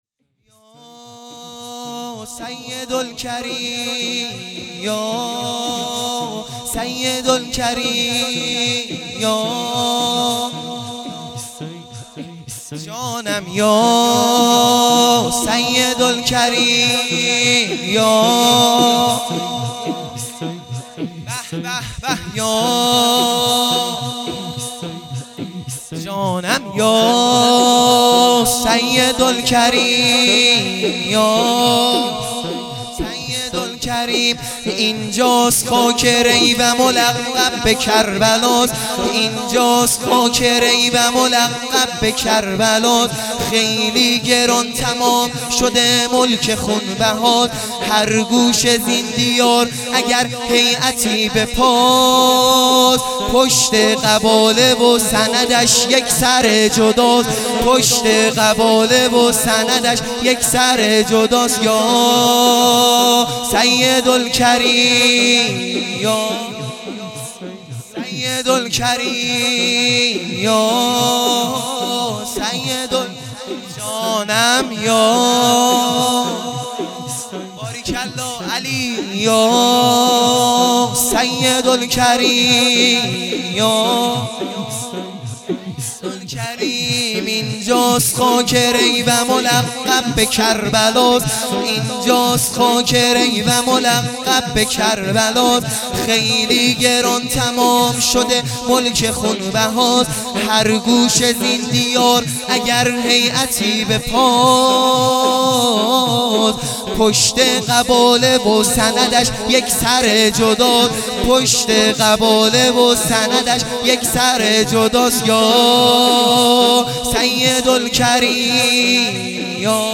زمینه | یا سیدالکریم